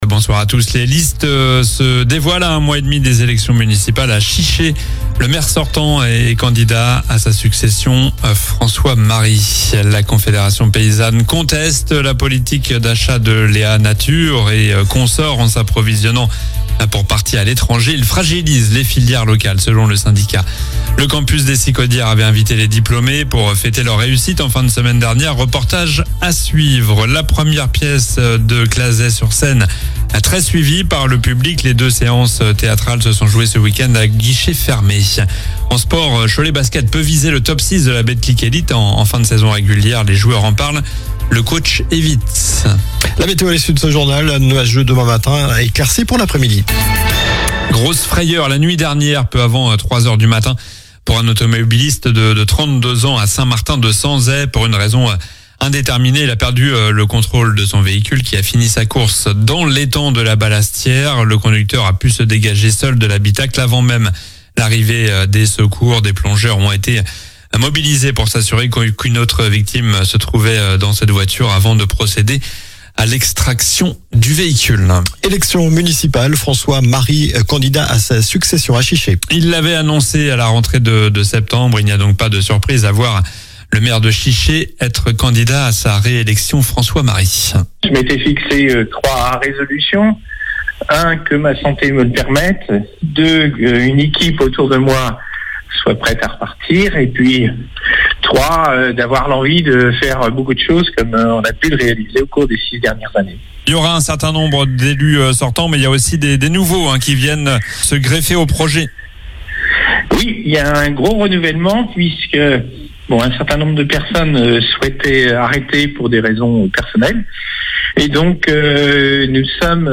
Journal du lundi 2 février (soir)